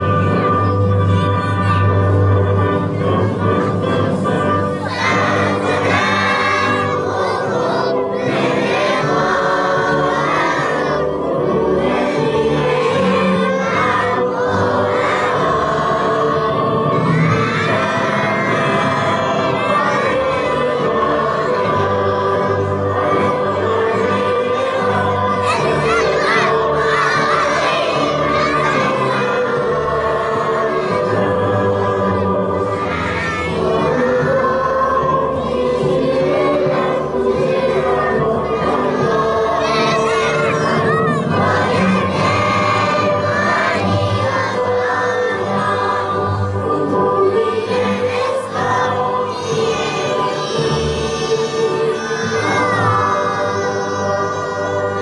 اجرای کودکان پیش دبستانی